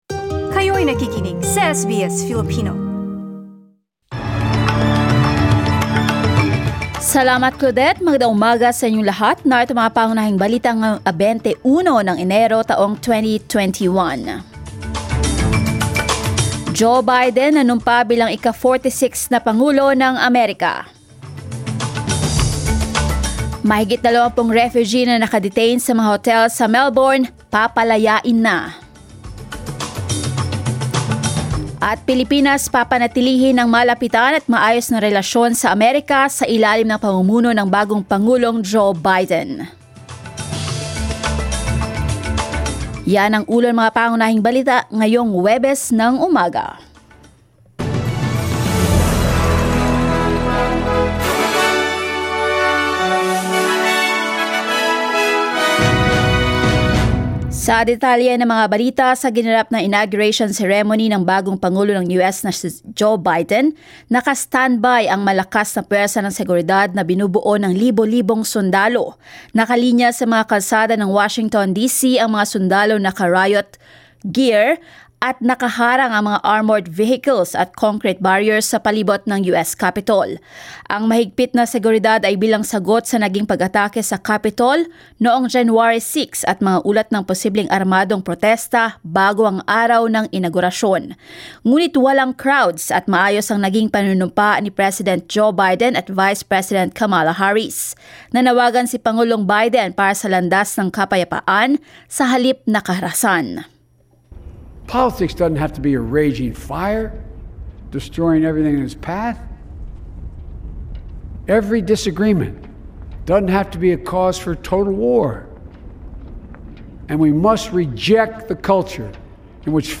SBS News in Filipino, Thursday 21 January